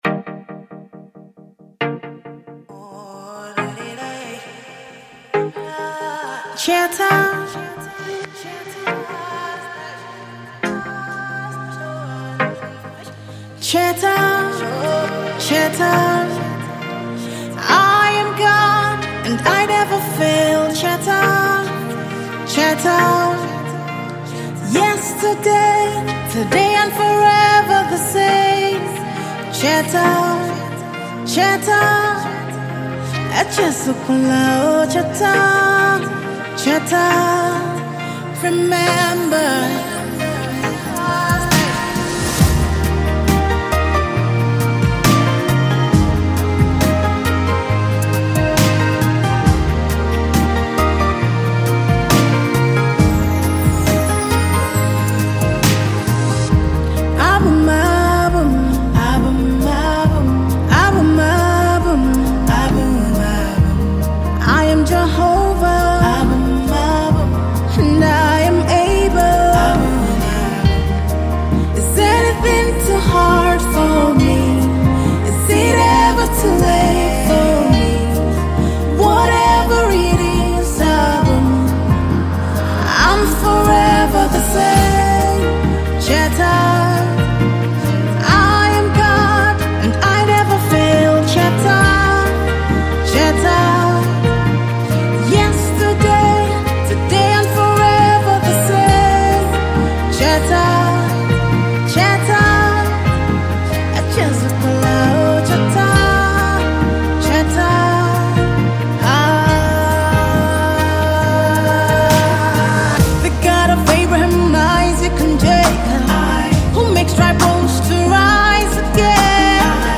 MP3 GOSPEL AUDIO & LYRICS